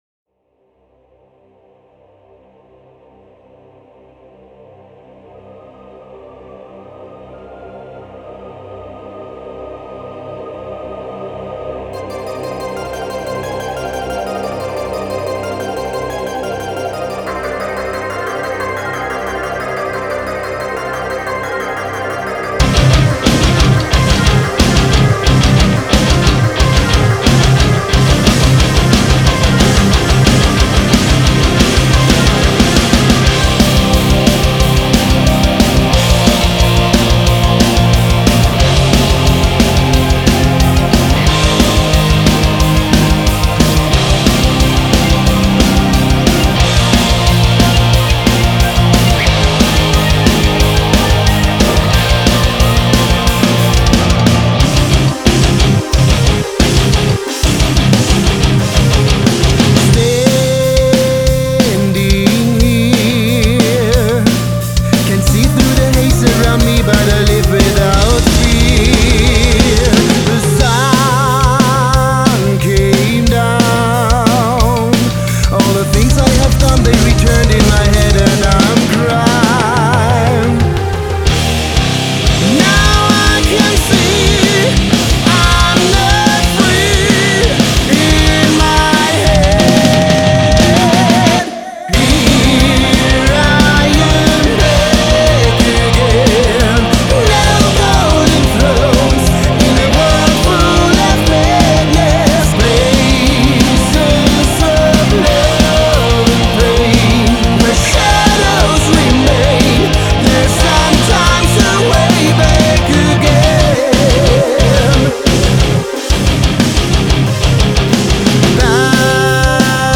Жанр: Heavy Metal